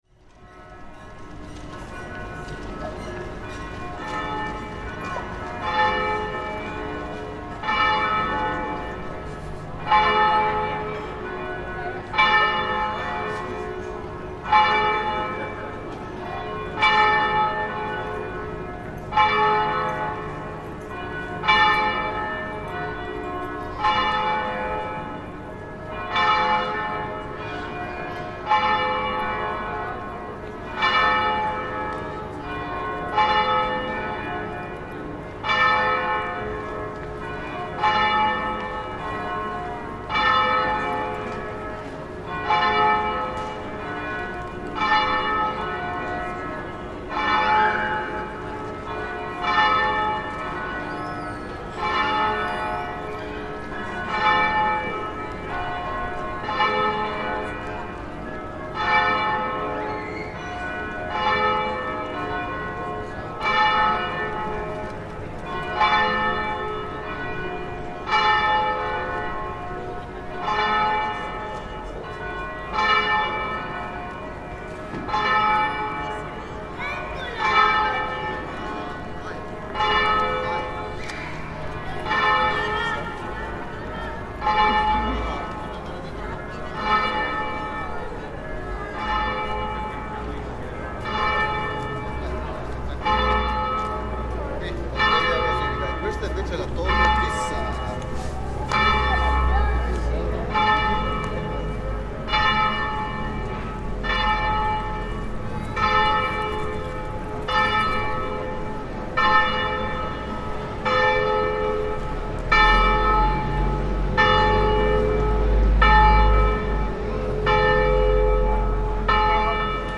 Chorus of bells
At midday, a chorus of different church bells from across the city ring out in the splendid Piazza della Biade in Vicenza.